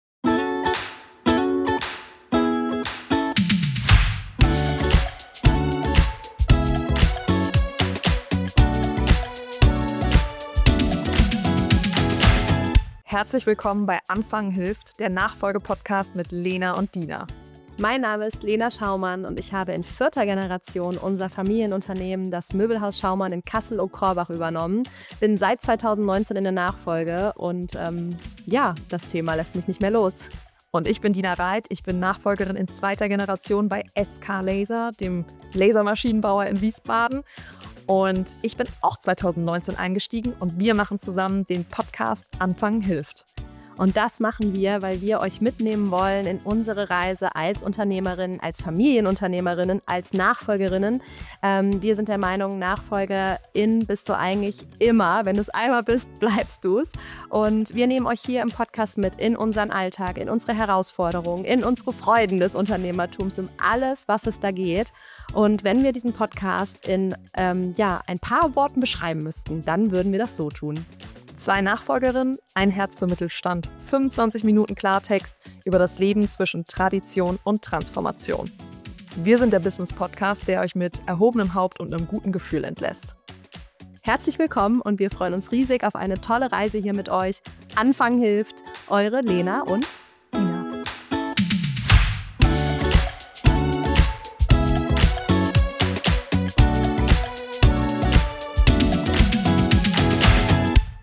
Zwei Nachfolgerinnen.